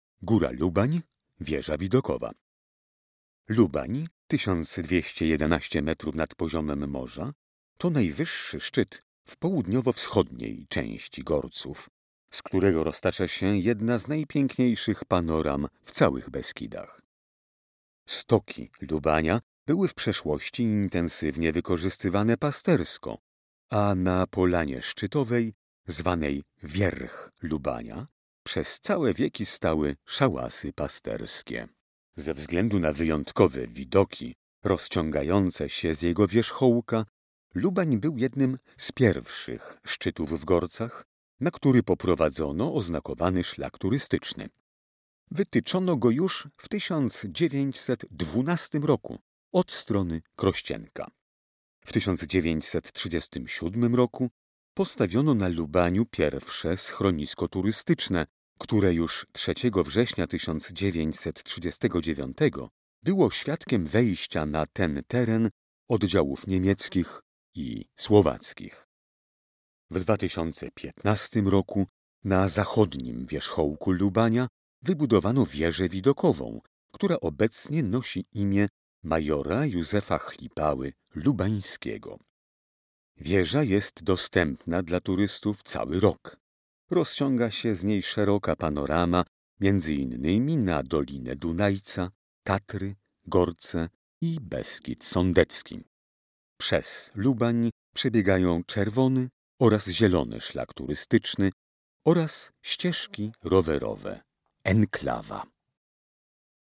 Opis miejsca w wersji audio